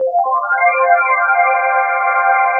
Index of /90_sSampleCDs/Best Service ProSamples vol.10 - House [AKAI] 1CD/Partition C/PADS